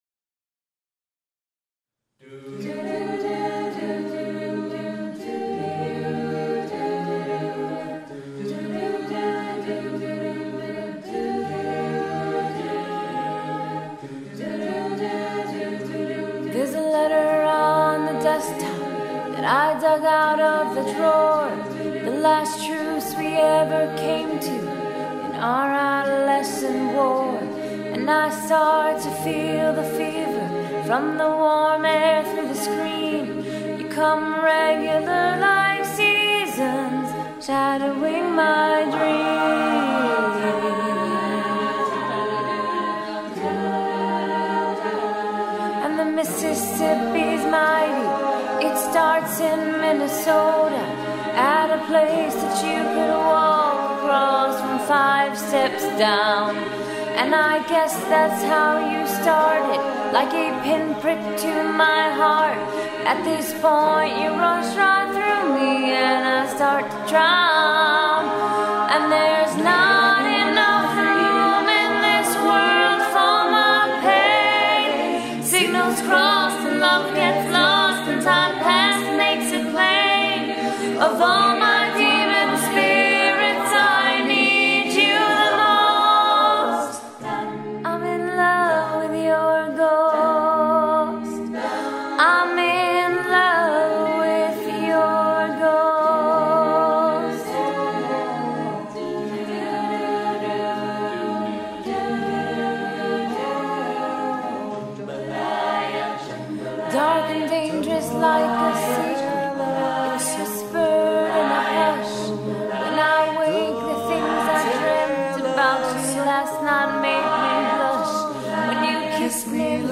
Genre: Secular
Contains solos: Yes